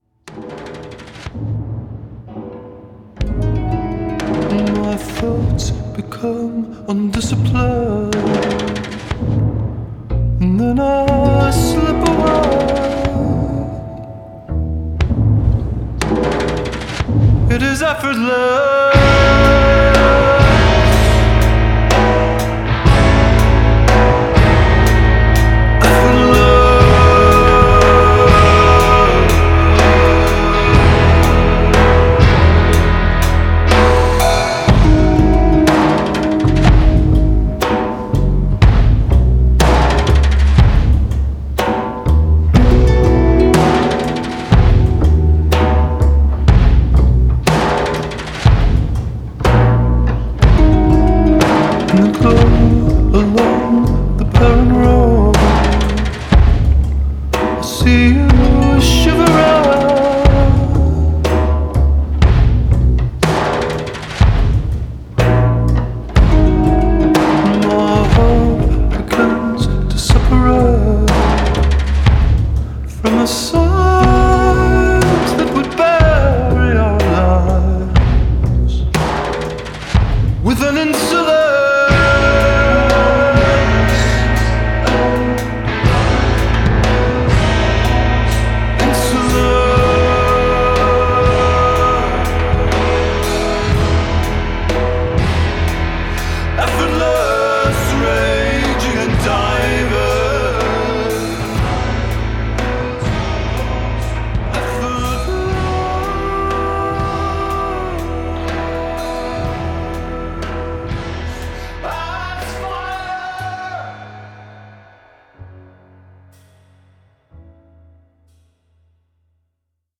controlled power riding on soaring melodies